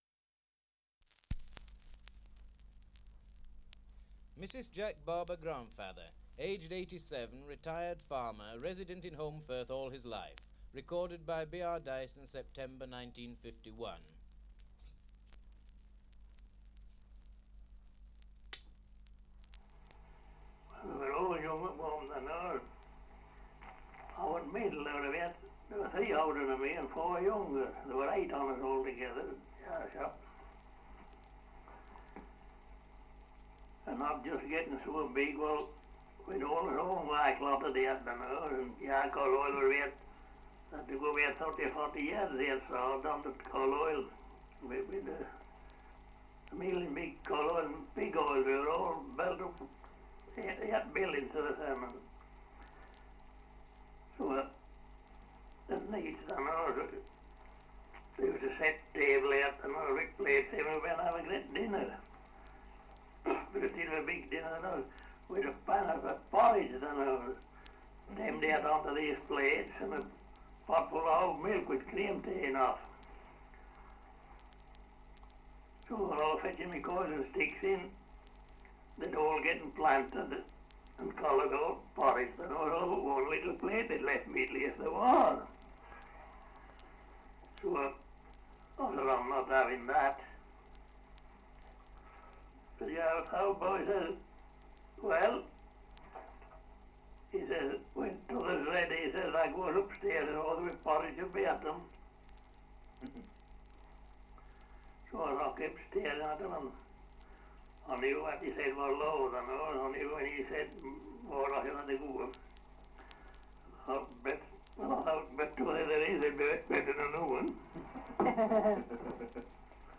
Dialect recording in Holmbridge, Yorkshire
78 r.p.m., cellulose nitrate on aluminium